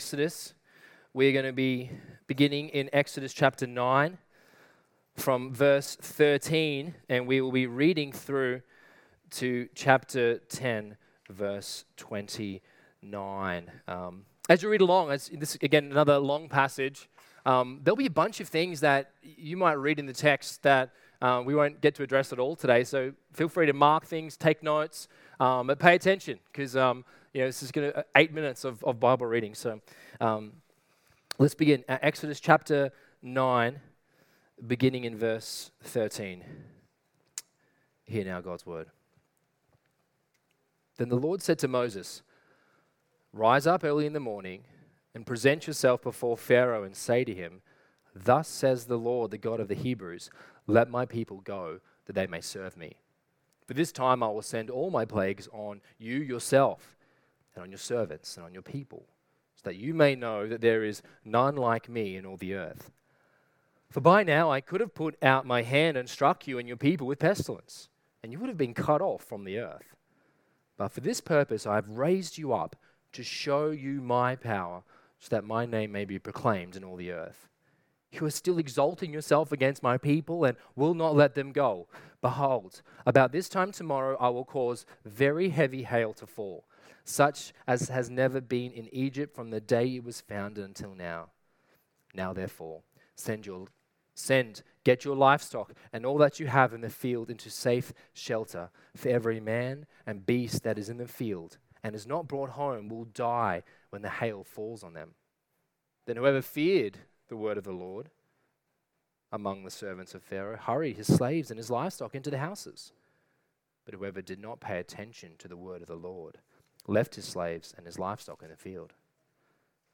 Sermons | Coomera Baptist Church